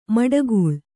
♪ maḍagūḷ